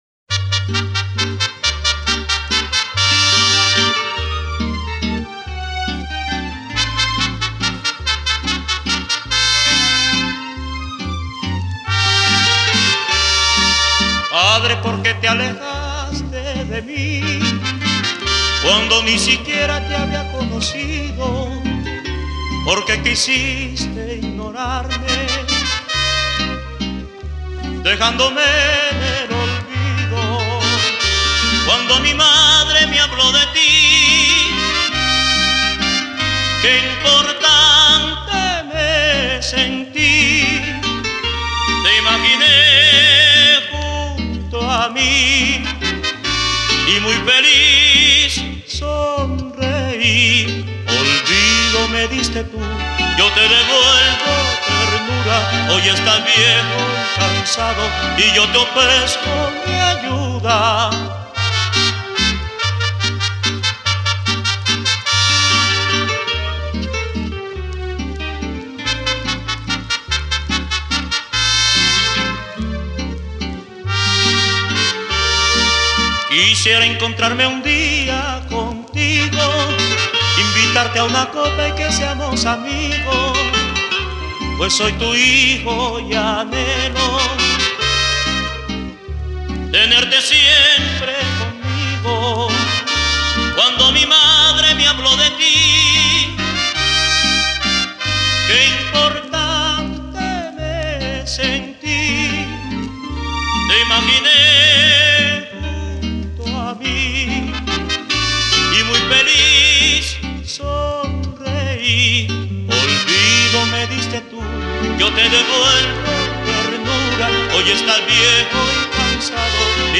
Ranchera